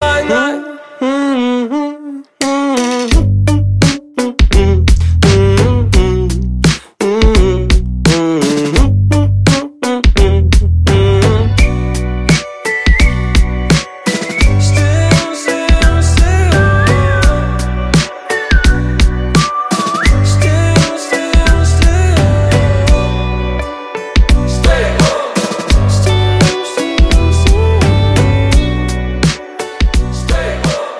поп , танцевальные , легкие , свист